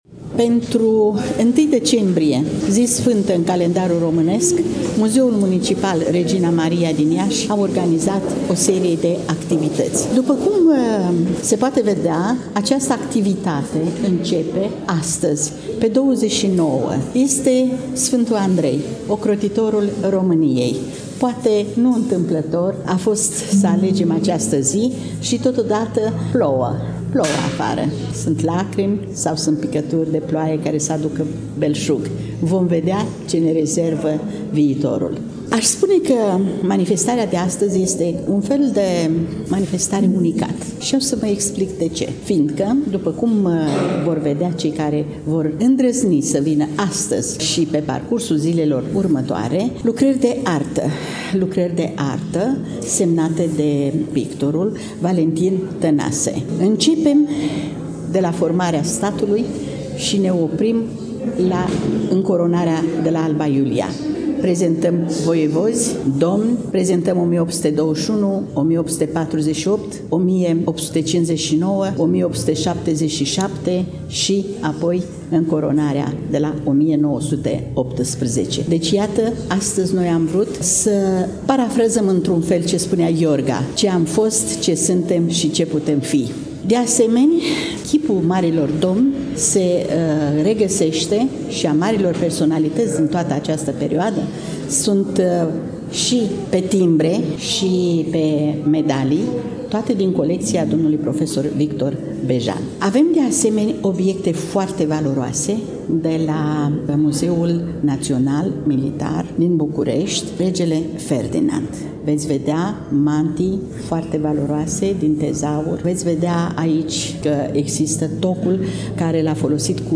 Manifestarea a avut loc în ziua de vineri, 29 noiembrie 2024, începând cu ora 11 în incinta Palatul Braunstein din târgul Iașului.